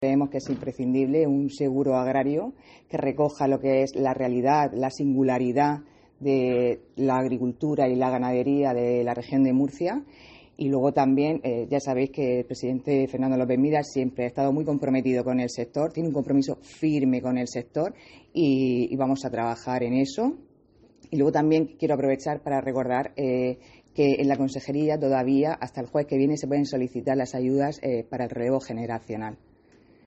Sara Rubira, consejera de Agricultura